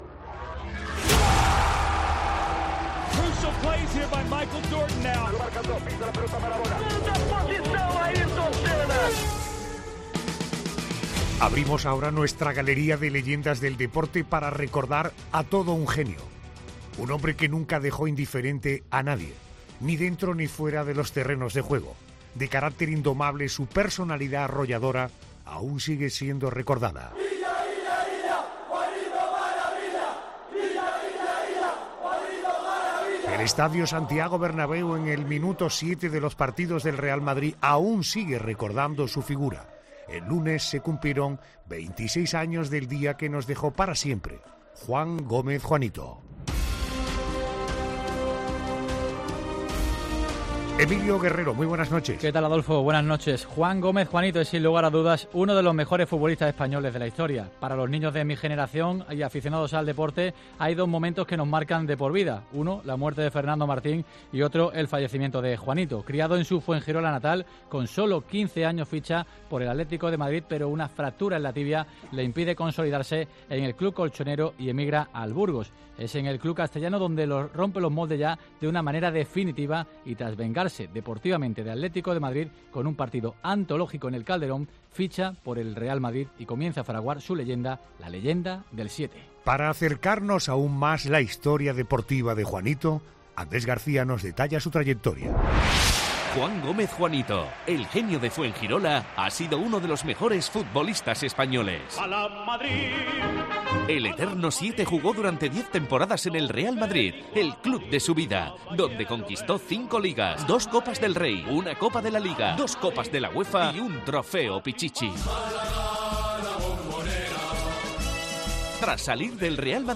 En 'La Noche de Cope' hemos rescatado una entrevista que mantuvo con la inolvidable Encarna Sánchez en 1987 y donde se demuestra la personalidad del malogrado futbolista.